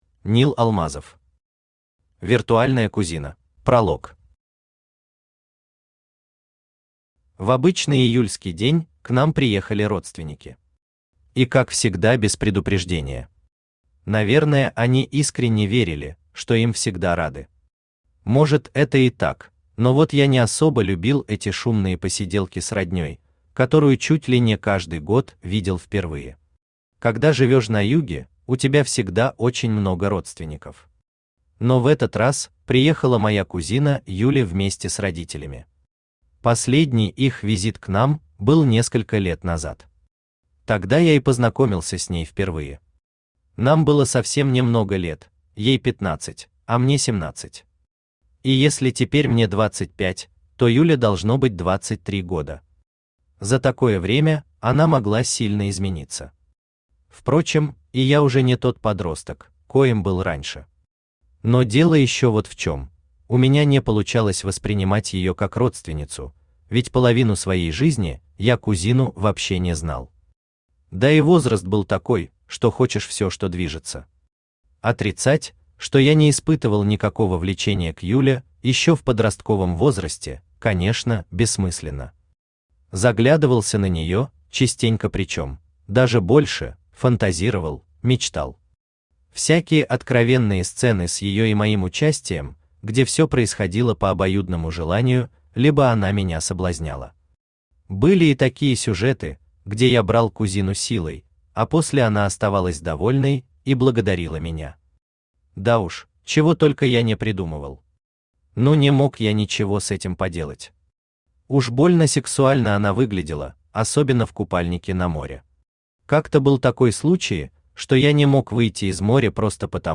Аудиокнига Виртуальная кузина | Библиотека аудиокниг
Aудиокнига Виртуальная кузина Автор Нил Алмазов Читает аудиокнигу Авточтец ЛитРес.